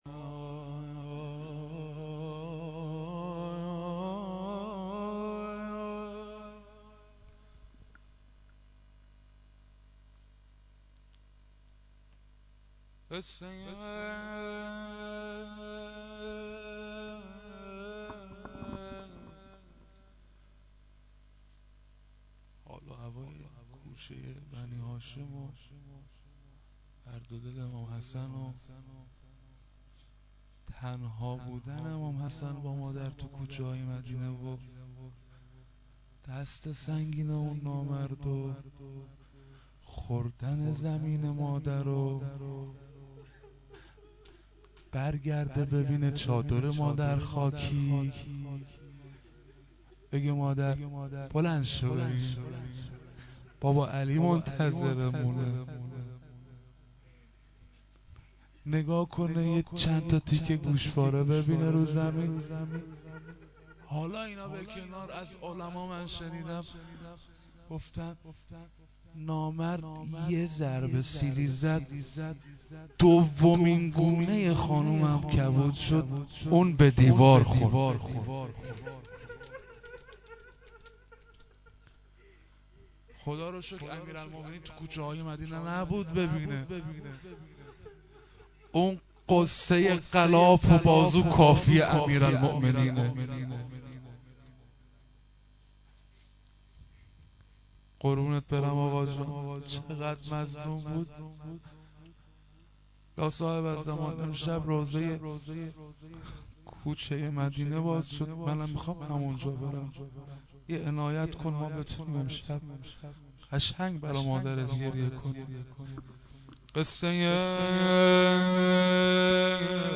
fatemie-aval-esfand-93-sh-2-rozeh.mp3